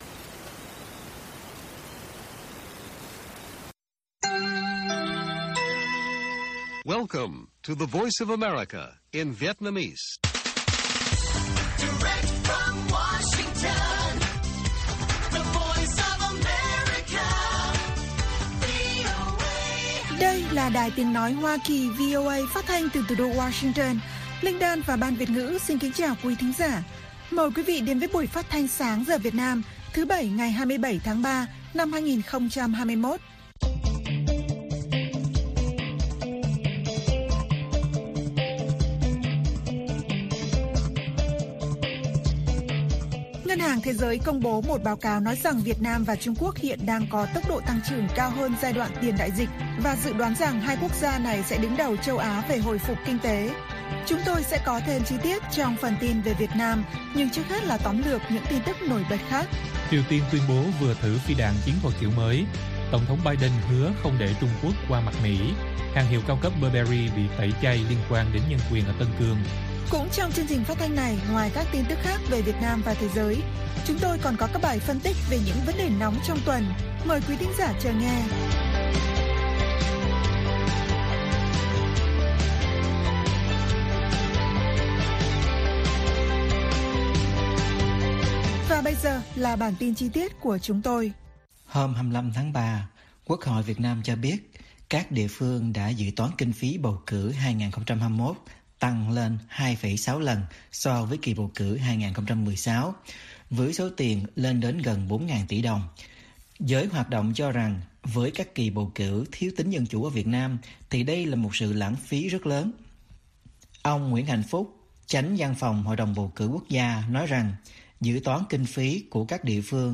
Bản tin VOA ngày 27/3/2021